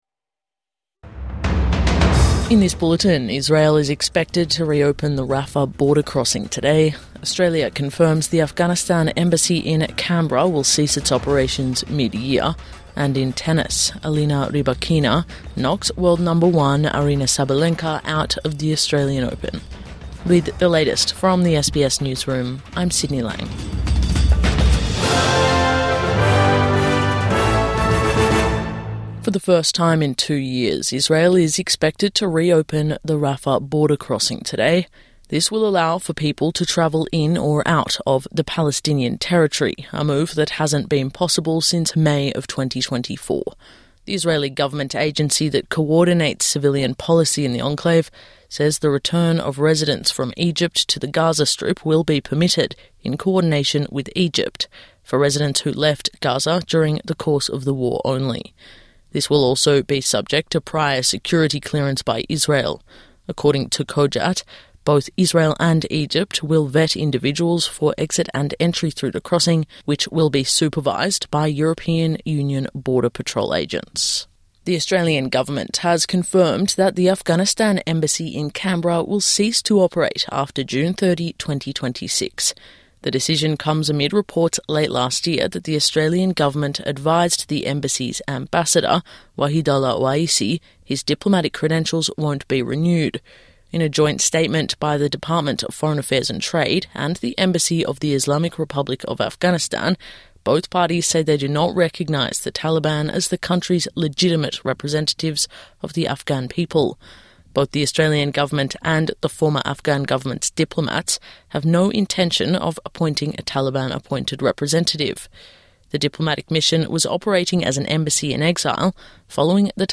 Israel expected to re-open the Rafah border crossing | Morning News Bulletin 1 February 2026